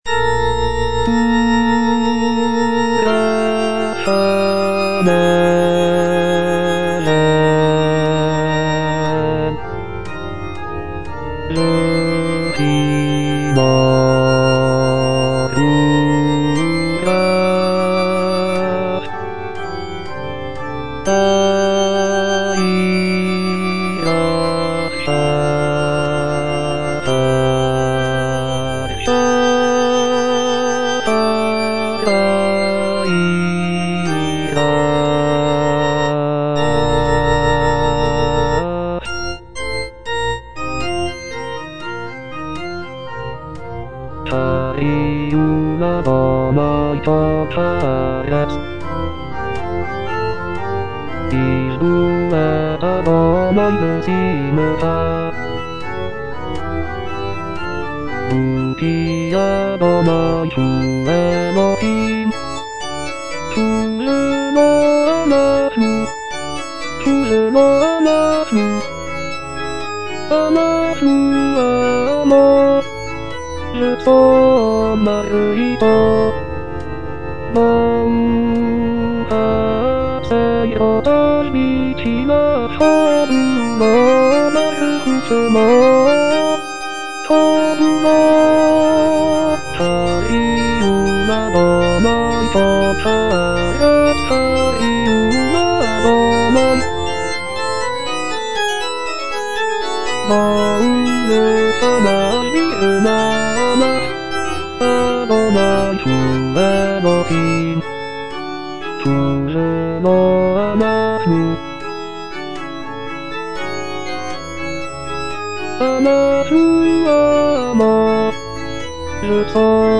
bass II) (Voice with metronome